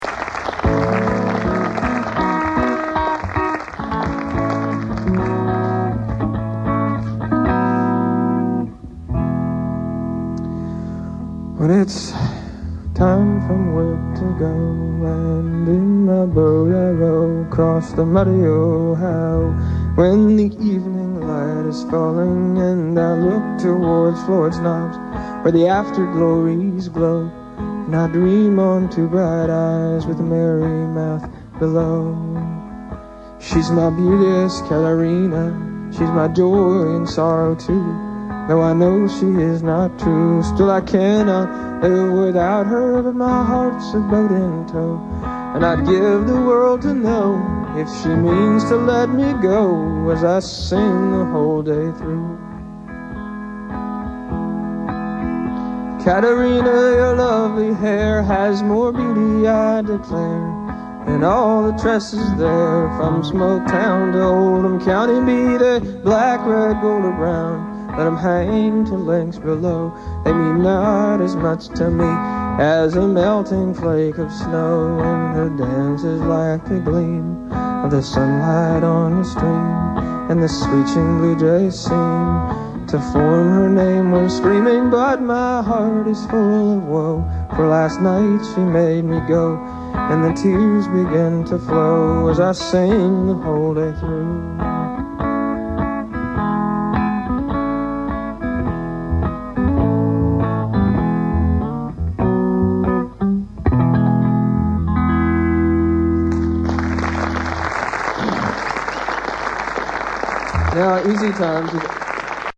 enregistrée le 26/01/1999  au Studio 105